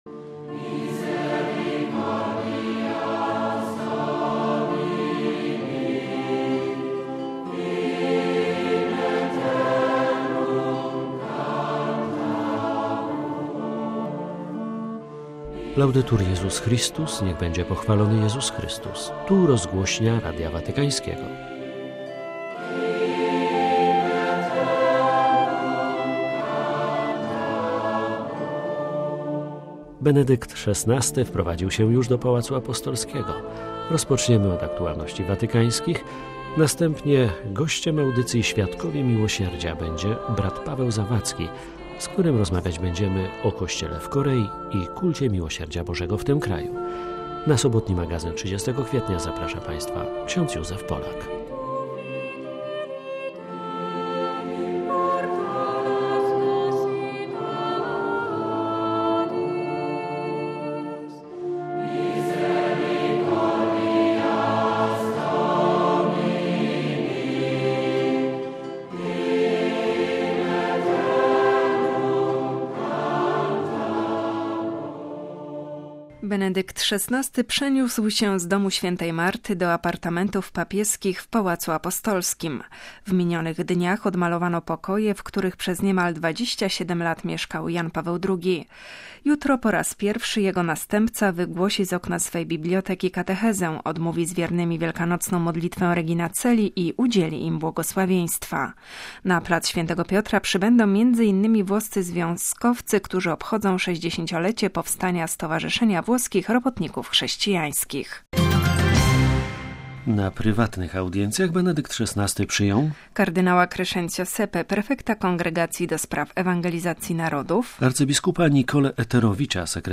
watykańskie aktualności; - rozmowa